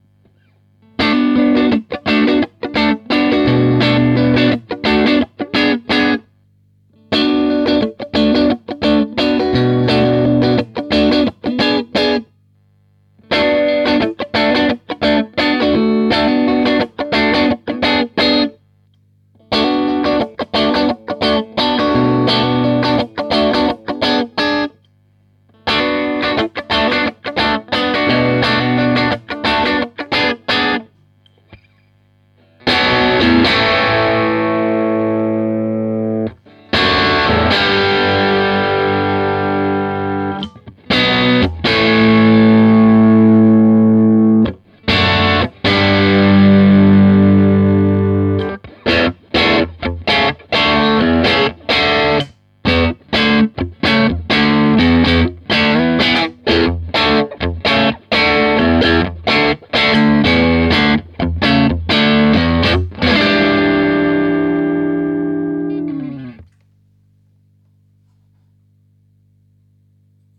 In meinen Ohren geht der Kleine ziemlich britisch zu Werke, was durch den Greenback in meinem Fall wahrscheinlich auch noch mehr betont wird.
Ich schalte durch alle Pickup-Positionen, jeweils mit  runter geregeltem Volume und danach voll aufgedreht. Die Einstellungen beim Amp waren bis auf den Gain-Regler (im Scheithauer-Clip voll auf, im Strat-Clip auf 12 Uhr) identisch.
Career/Felleretta 5 Watt - Luk Strat